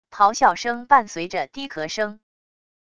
咆哮声伴随着低咳声wav音频